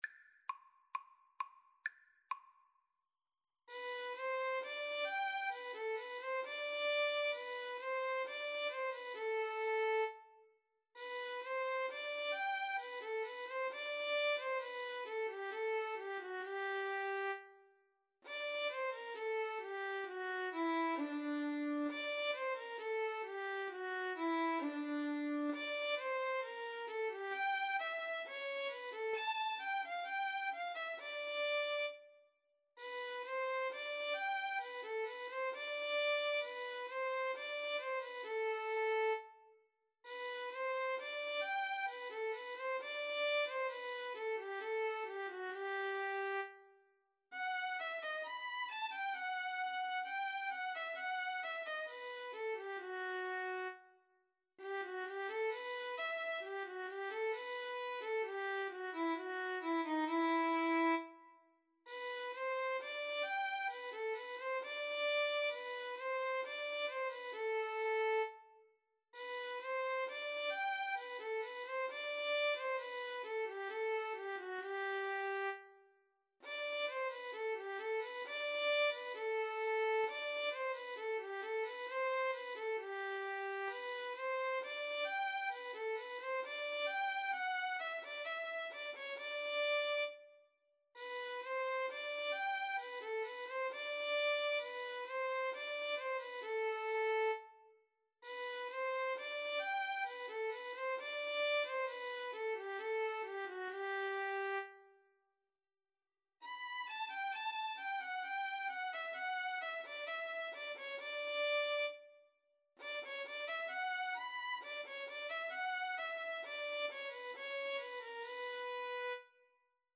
~ = 100 Allegretto =c.66
Classical (View more Classical Violin Duet Music)